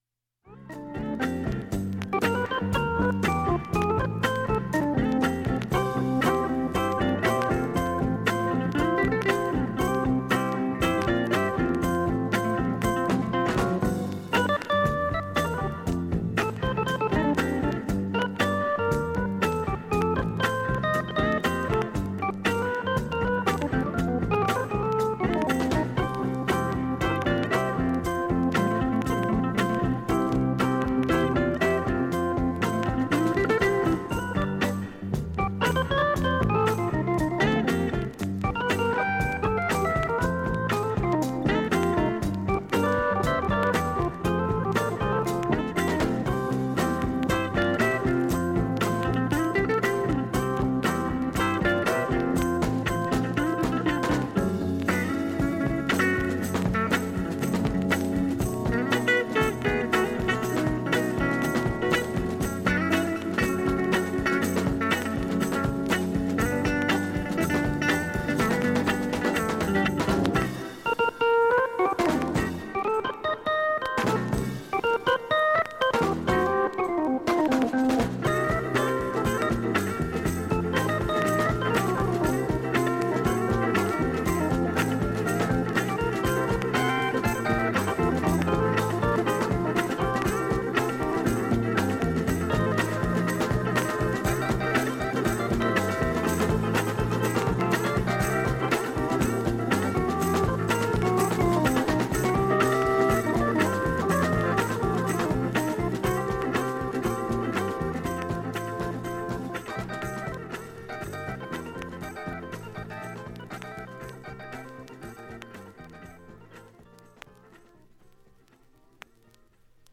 普通に聴けます音質良好全曲試聴済み。
ほか６回までのかすかなプツが5箇所(A-3,B-1,B-2×3)
３回までのかすかなプツが10箇所
単発のかすかなプツが5箇所